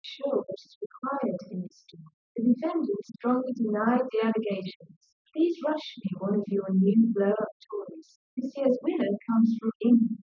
Simulated recordings with 4cm microphone spacing and 380ms reverberation time